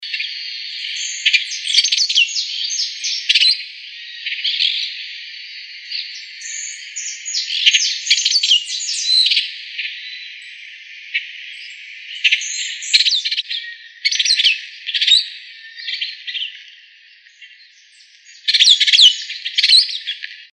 Brown-and-yellow Marshbird (Pseudoleistes virescens)
Spanish Name: Pecho Amarillo Común
Location or protected area: Reserva Natural de Uso Múltiple Isla Martín García
Condition: Wild
Certainty: Photographed, Recorded vocal